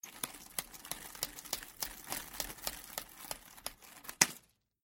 Крутим нунчаки одной рукой